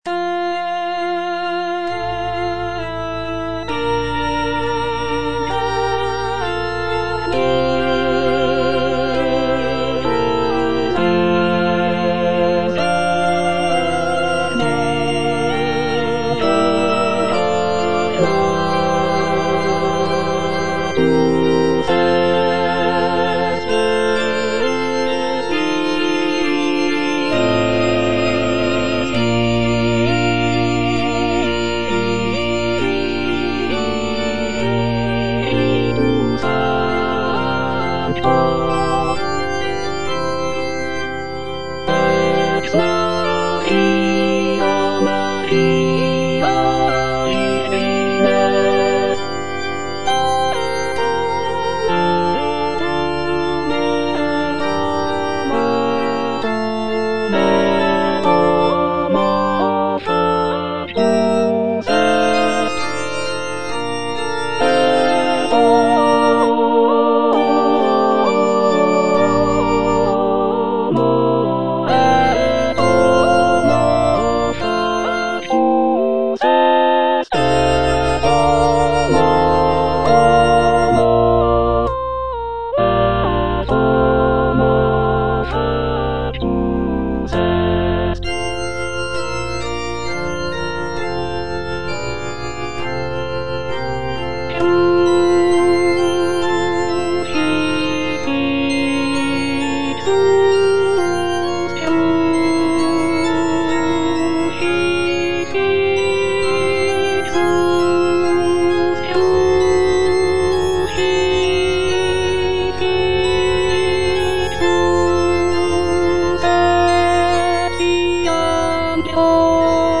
A. BRUCKNER - MISSA SOLEMNIS WAB29 6. Et incarnatus - Alto (Voice with metronome) Ads stop:
"Missa solemnis WAB29" is a sacred choral work composed by Austrian composer Anton Bruckner in 1854.